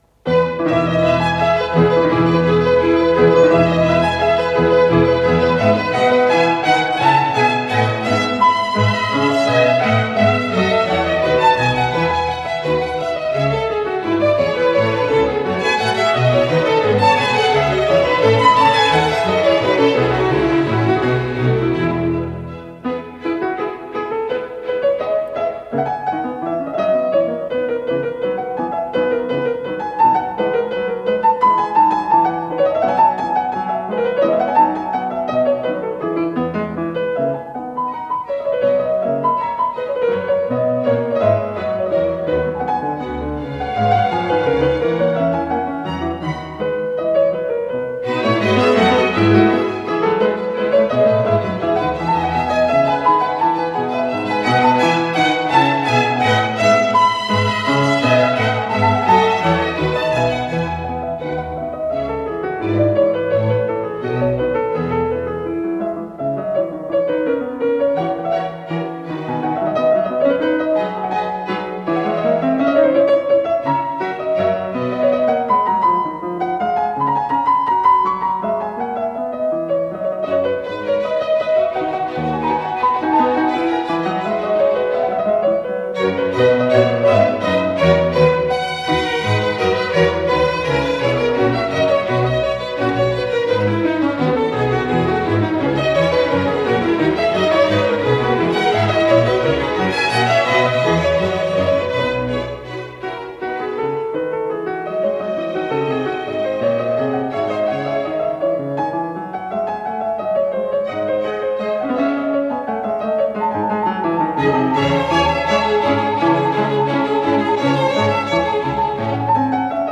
с профессиональной магнитной ленты
Аллегро
ИсполнителиВассо Деветци - фортепиано (Франция)
АккомпаниментМосковский камерный оркестр
Художественный руководитель и дирижёр - Р. Баршай
ВариантДубль моно